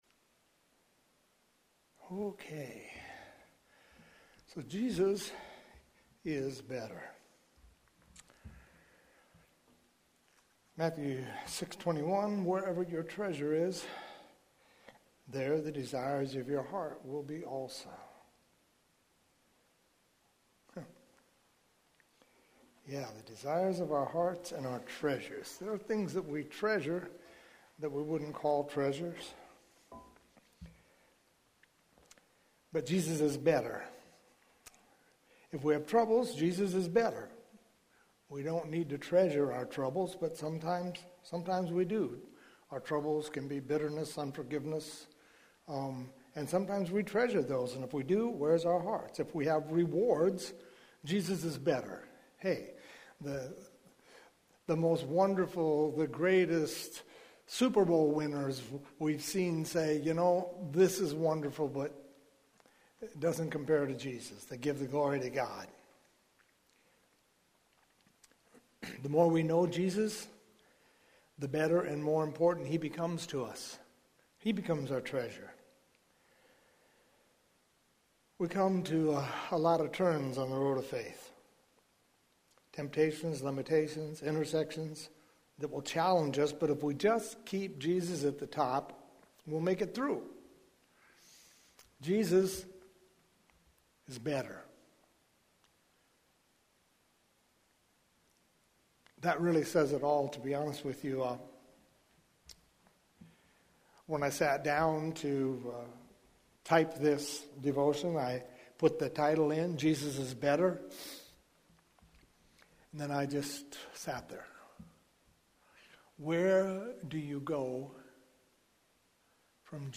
Longer sermons are broken up into smaller...